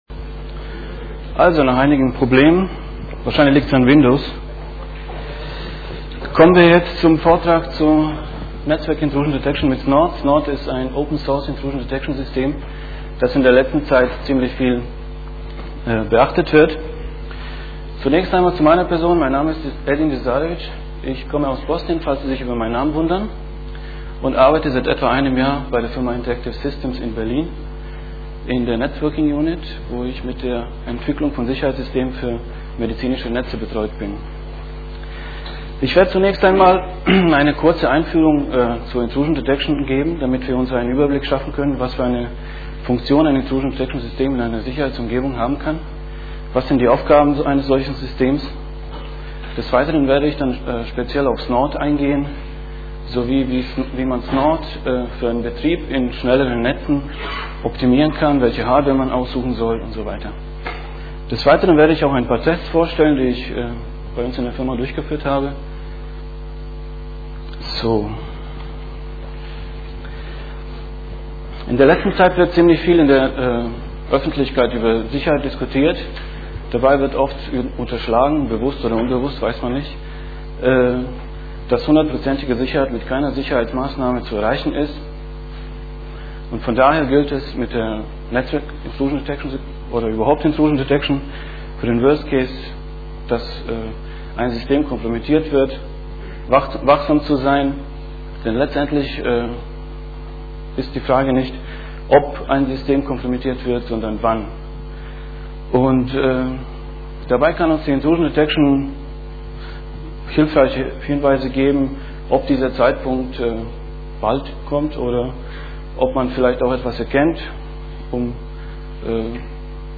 Der 5. Chemnitzer Linux-Tag ist eine der größten Veranstaltungen seinder Art in Deutschland.
Sonntag, 11:00 Uhr im Raum V2 - Sicherheit